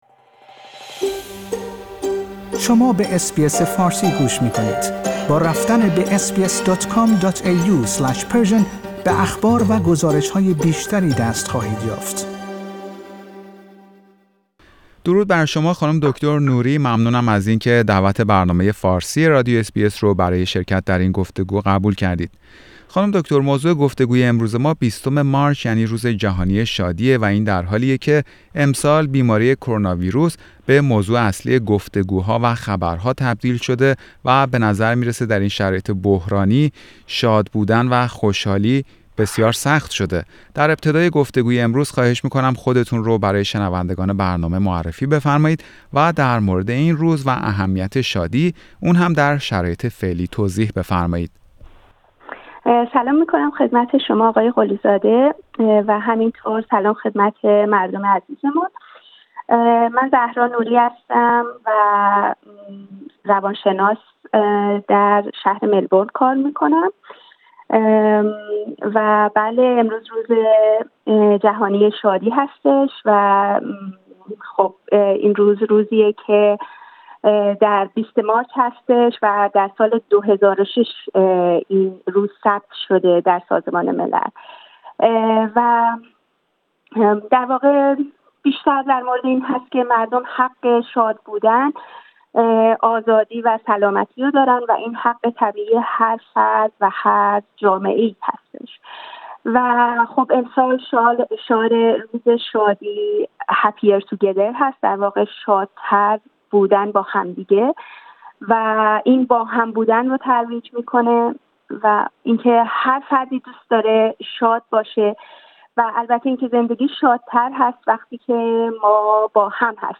برنامه فارسی رادیو اس بی اس در همین خصوص گفتگویی داشت